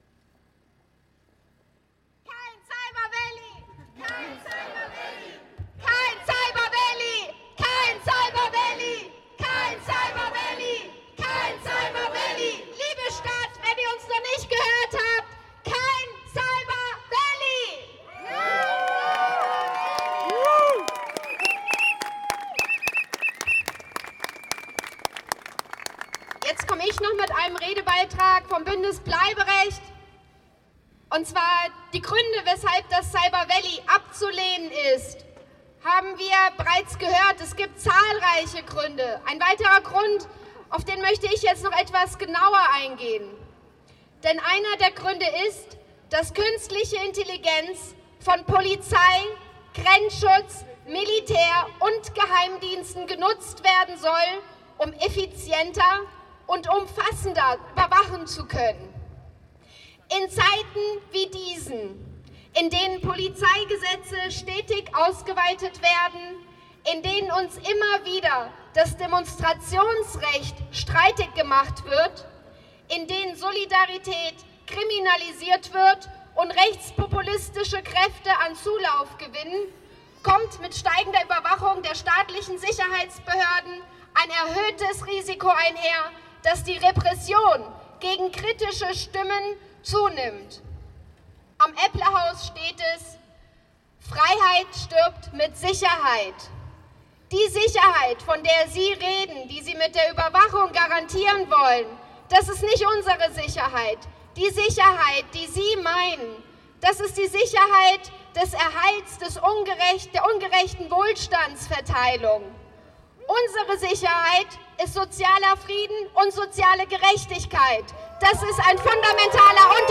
Bündnis Bleiberecht Beitrag vom Martplatz
66824_Buendnis_Bleiberecht_Beitrag_vom_Martplatz.mp3